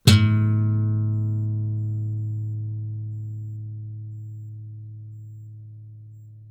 Quinta cuerda de una guitarra
la
cordófono
guitarra